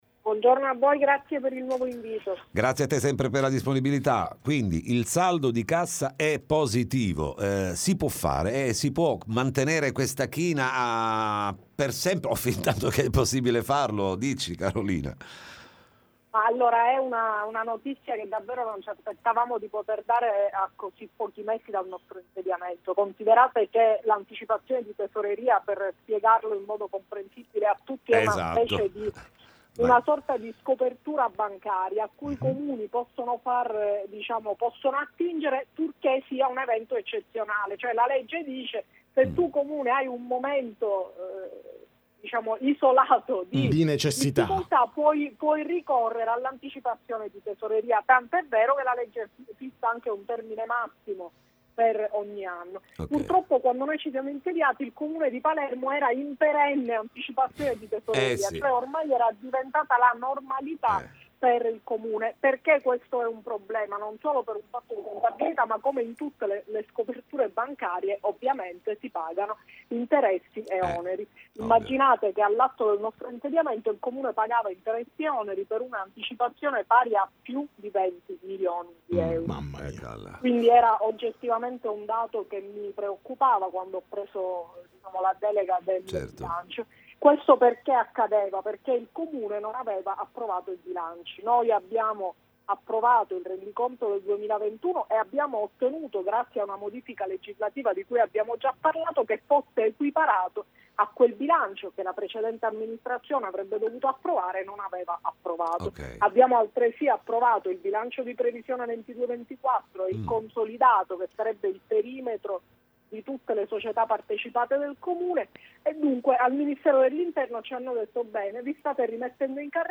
TM Intervista Carolina Varchi